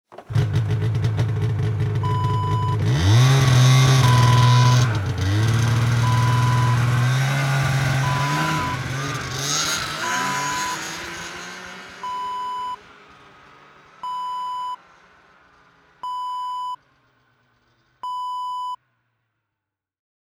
Snowmobile: start & take off sound effect .wav #2
Description: Snowmobile starts and takes off
Properties: 48.000 kHz 24-bit Stereo
A beep sound is embedded in the audio preview file but it is not present in the high resolution downloadable wav file.
snowmobile-take-off-preview-2.mp3